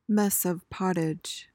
PRONUNCIATION:
(MES uhv POT-ij)